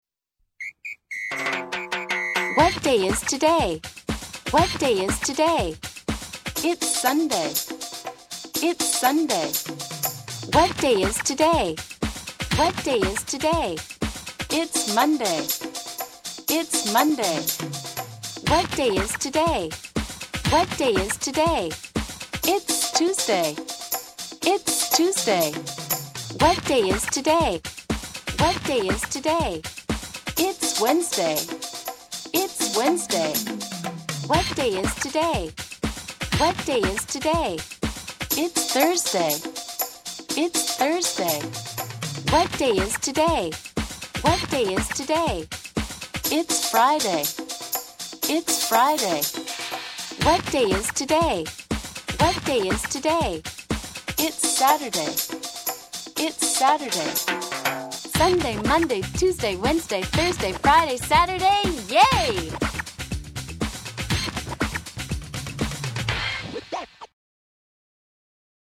第1部では単語を構文に入れネイティブスピーカーが発音しています。楽しいチャンツや“ABCの歌”も収録されています。第2部では各レッスンをネイティブスピーカーが2度ずつ発音しています。とくに会話のレッスンでは、ネイティブスピーカーがナチュラルスピードで発音しています。これは子どもに会話のリズムをつかませるためです。